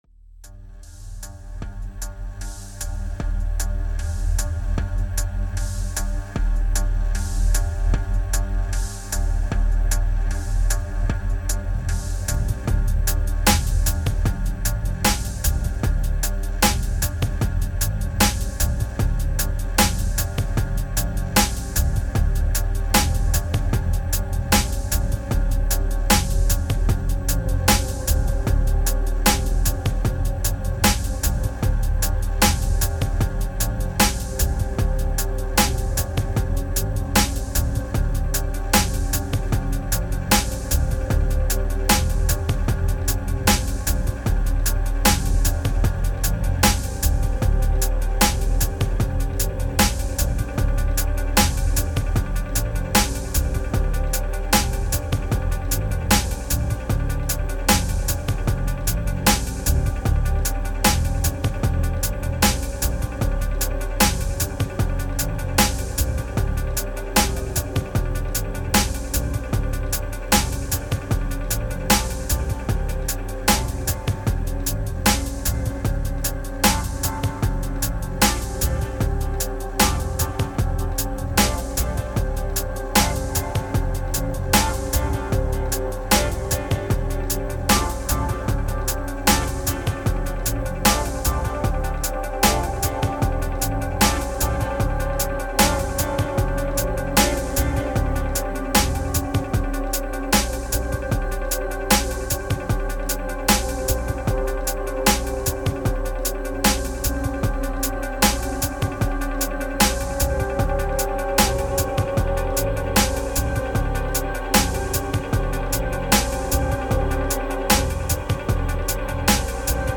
Phat morning beat. One take. Headz mandatory. Peace